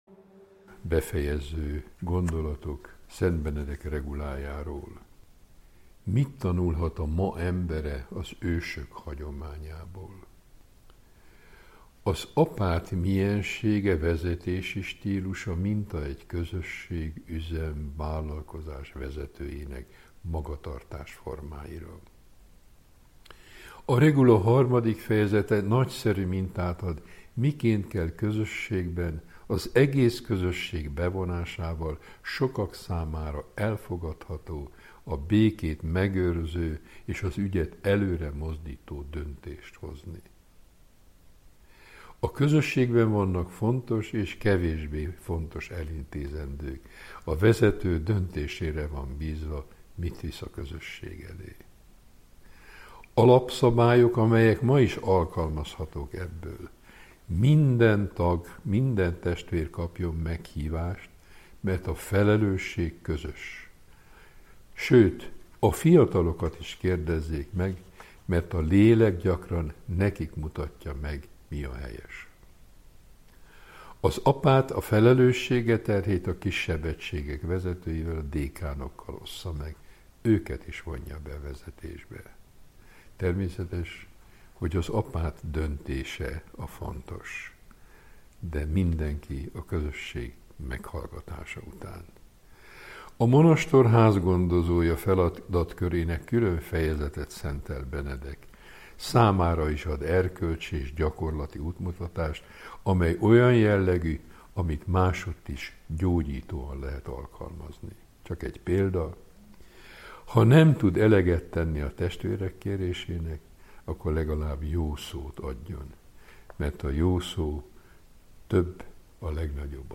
Szent Benedek öröksége Várszegi Asztrik emeritus pannonhalmi főapát tolmácsolásában